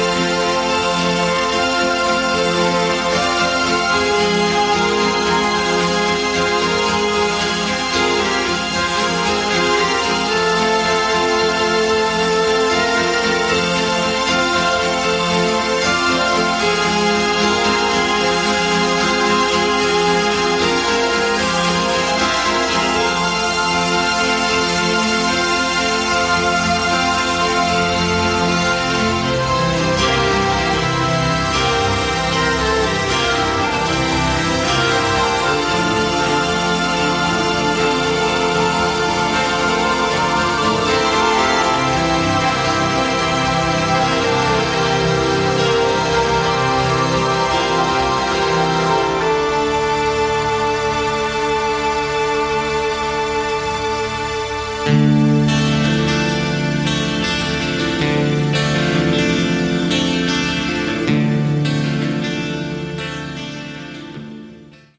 Category: AOR
lead vocals, guitar
lead and rhythm guitar, backing vocals
drums
additional keyboards